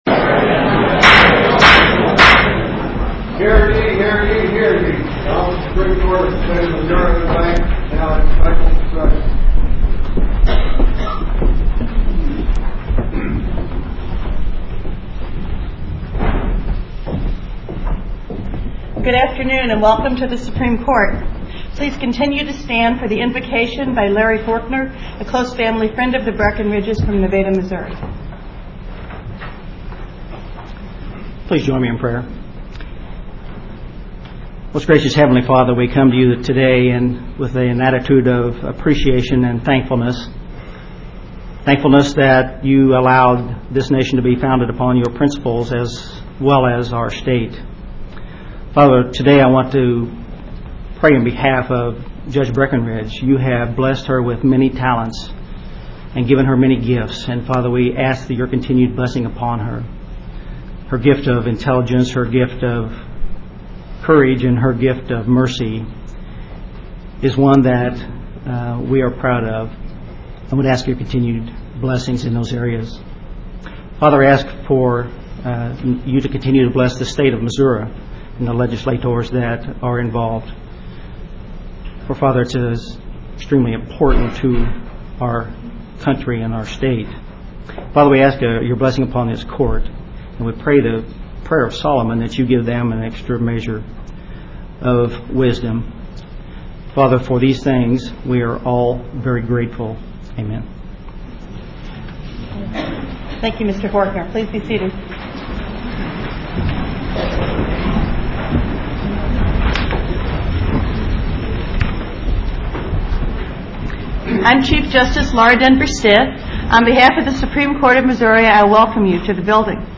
Chief Justice Laura Denvir Stith presided over the formal ceremony in the Supreme Court's en banc courtroom in Jefferson City.